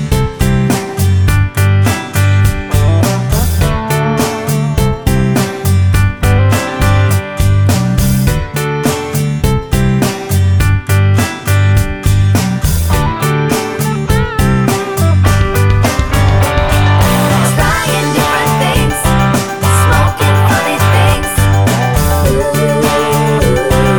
Minus Main Guitar Rock 3:17 Buy £1.50